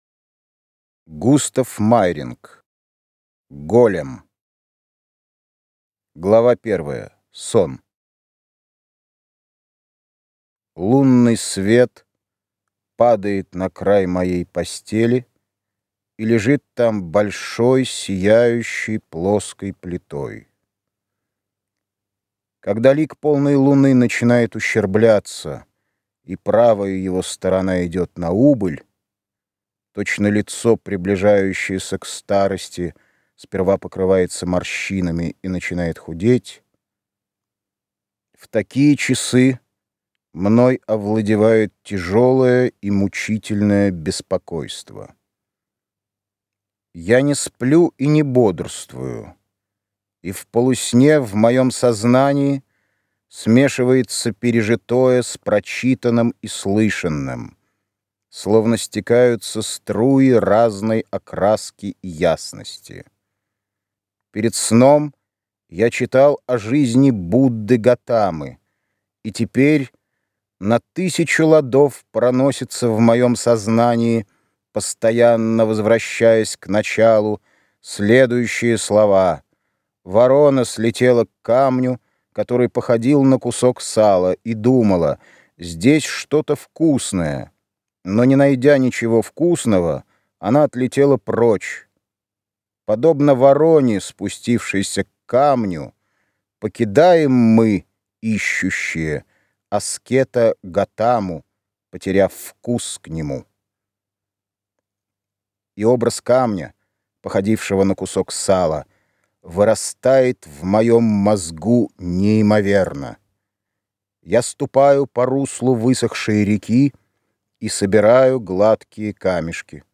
Аудиокнига Голем | Библиотека аудиокниг